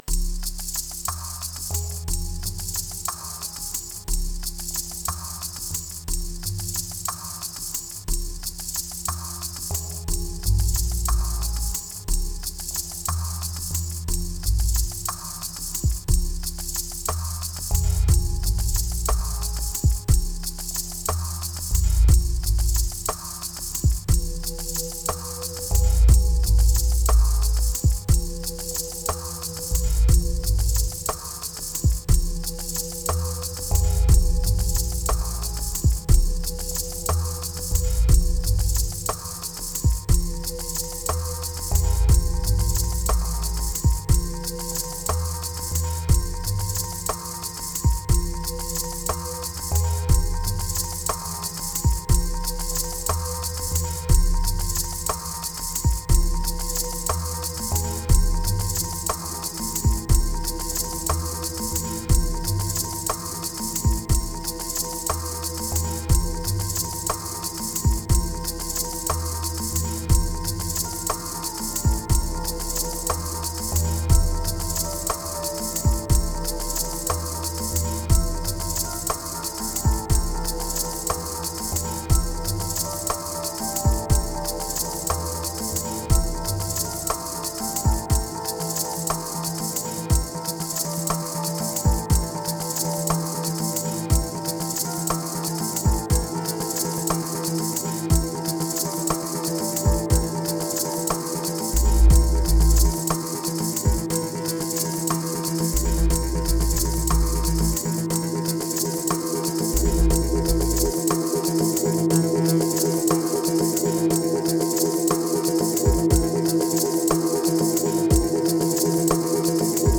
Triphop Beats Moods Seldom Attic Crush Enclosure Reveal Heal